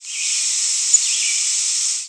Brown Creeper Certhia americana
Flight call description A high, soft "tsf".
Perched bird with Red-eyed Vireo singing in the background.
Very weak flight calls and "seee" amidst song of Red-eyed Vireo and calls of Great Crested Flycatcher.  Also a Brown Creeper "seee" note in the cut.